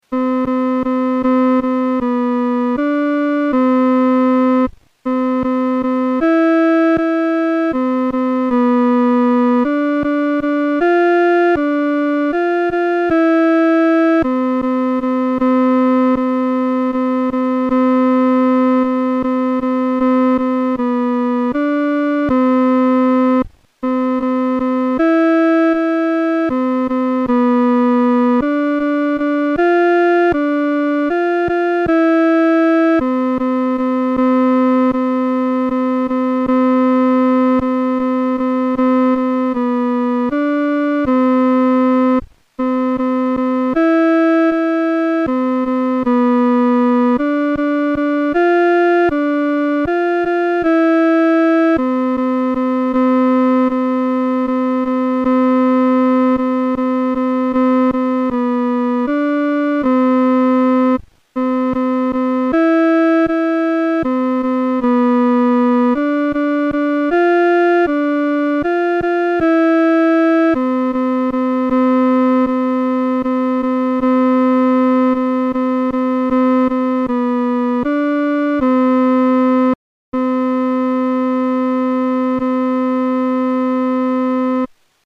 伴奏
其旋律、和声构成无比宁静的气氛，在丧礼中给人莫大的安慰。